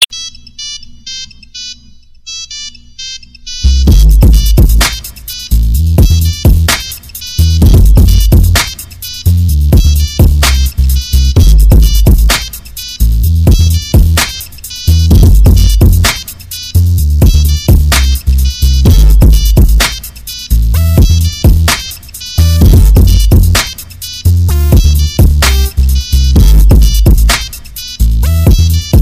Mix And Remix Tone